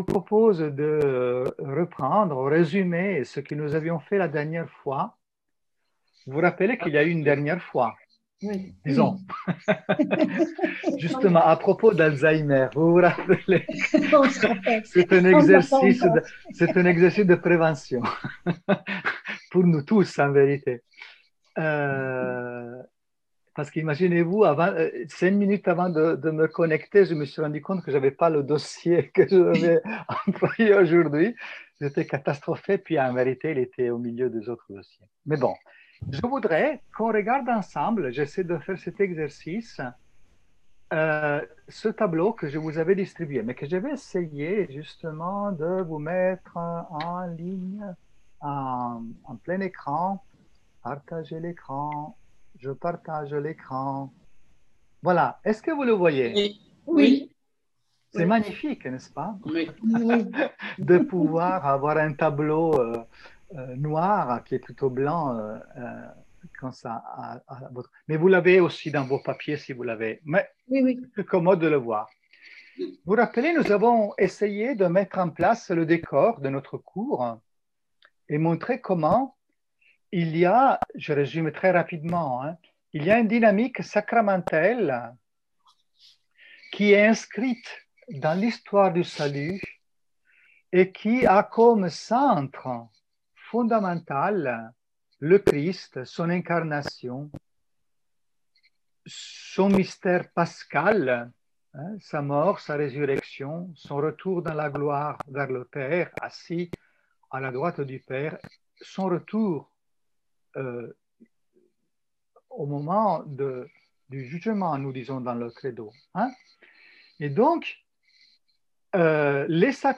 Cours audio n°2 IG du 19 01 21 OK